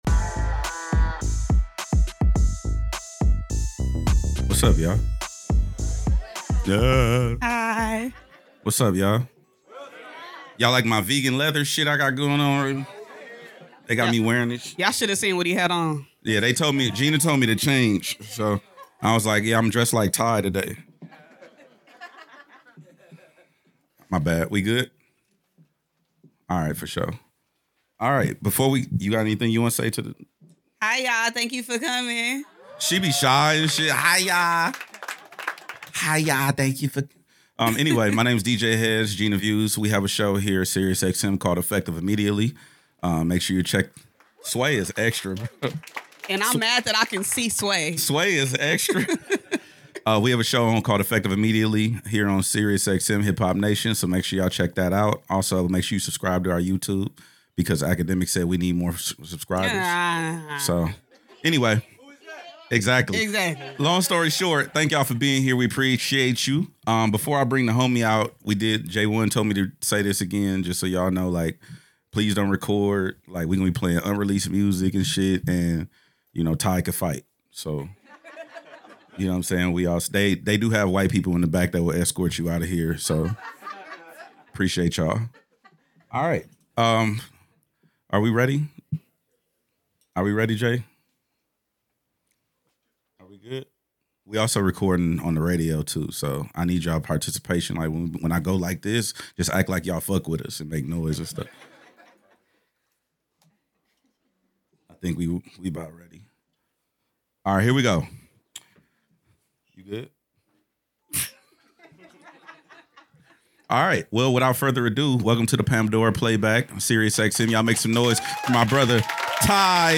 Effective Immediately is a nationally syndicated radio show and podcast that serves as the ultimate destination for cultural conversations, exclusive interviews, and relevant content. Hosted by radio and television veteran DJ Hed and new media superstar Gina Views, the show is dedicated to injecting integrity and authenticity back into the media landscape.